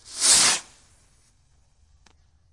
烟花 " 瓶装火箭03
描述：使用Tascam DR05板载麦克风和Tascam DR60的组合使用立体声领夹式麦克风和Sennheiser MD421录制烟花。我用Izotope RX 5删除了一些声音，然后用EQ添加了一些低音和高清晰度。
Tag: 高手 焰火 裂纹